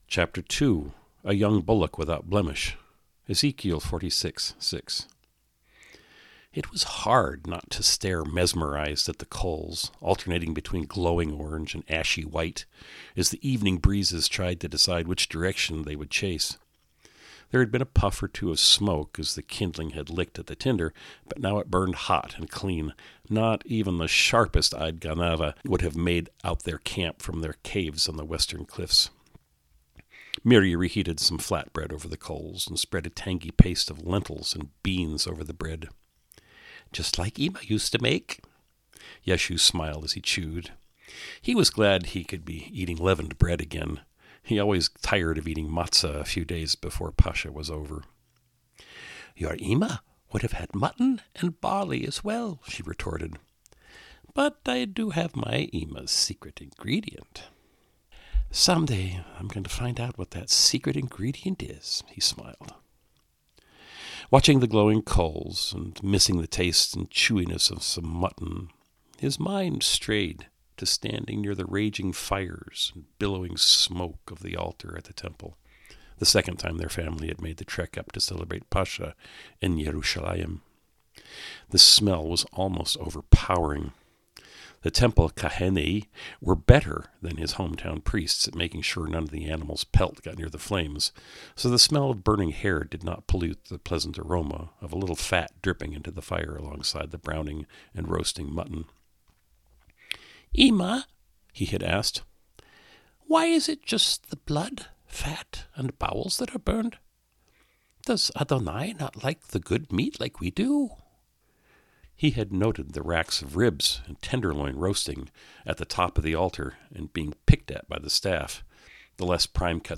Audiobook | $14.99